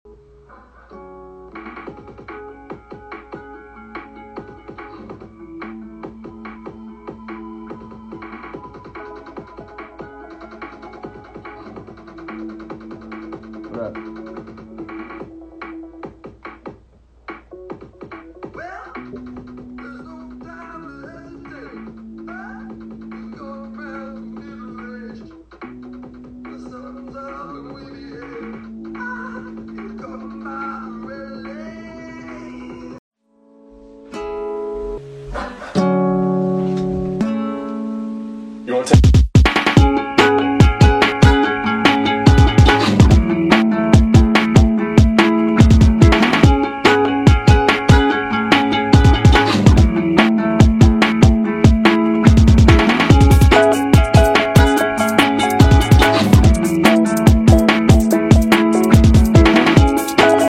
from an Instagram Live from over 5 years ago